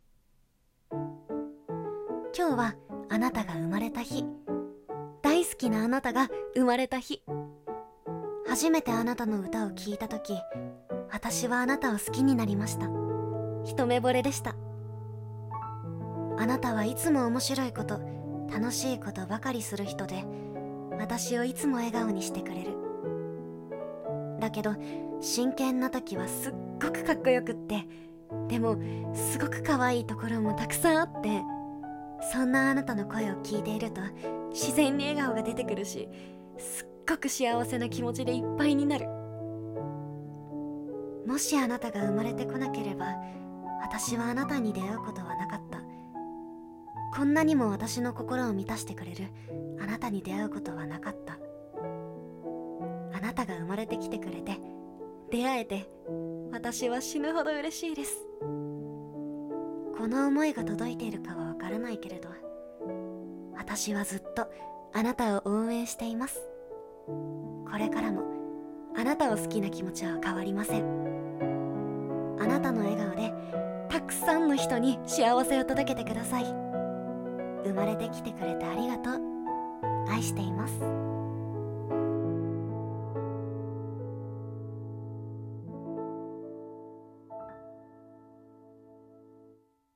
【一人声劇】貴方の産まれた日